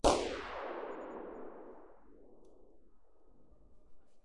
mech_fire_phase.ogg